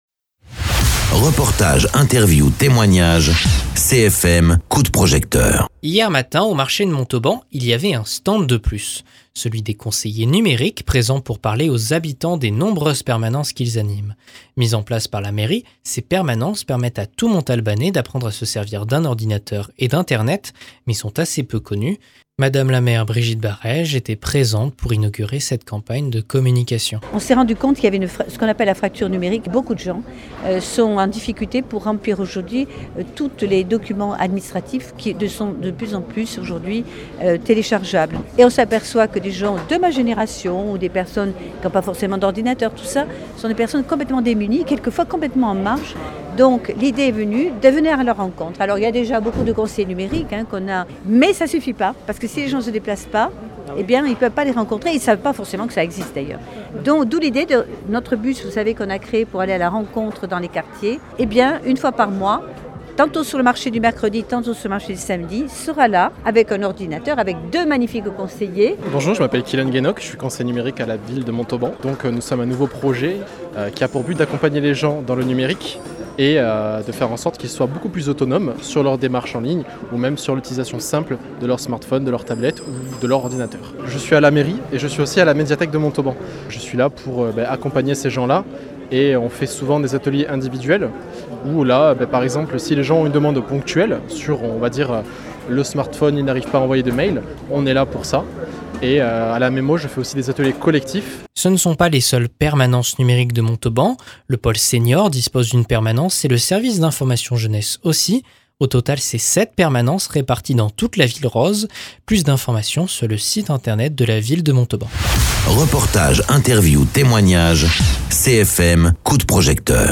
Interviews
Invité(s) : Brigitte Barèges, maire de Montauban